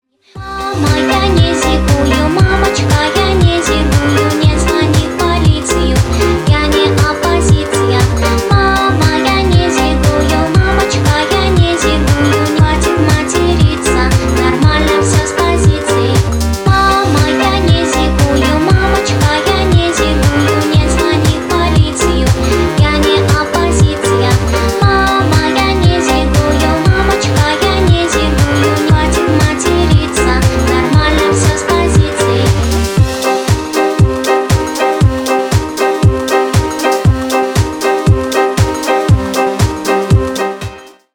милые
мелодичные
красивый женский голос
indie pop
озорные